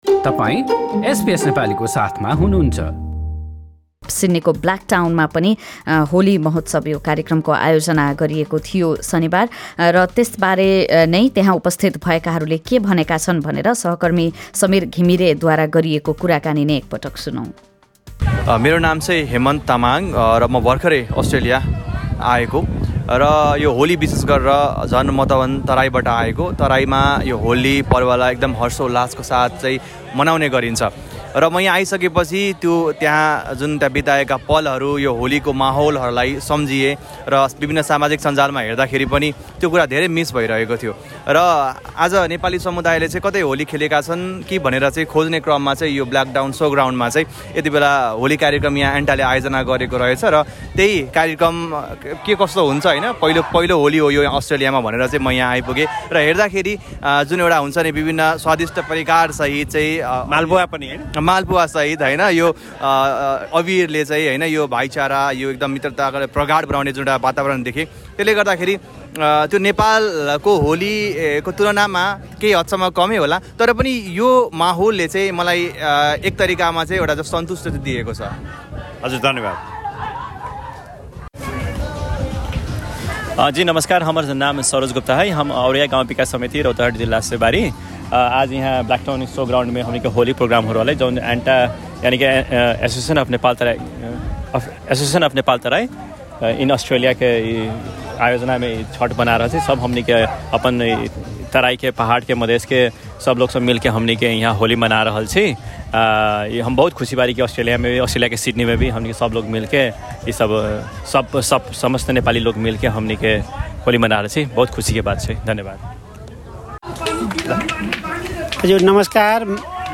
शनिवार सिड्नीको ब्लाकटाउनमा आयोजित होली कार्यक्रमका सहभागीले एसबीएस नेपालीसँग कुरा गर्दै सो कार्यक्रमले नेपालमा मनाएको होलीको जस्तै झल्को दिएको बताएका छन्। एसोसिएसन अफ नेपाल तराई इन अस्ट्रेलियाको आयोजनामा भएको कार्यक्रमका बारेमा सहभागीहरूको भनाइ सुन्नुहोस्।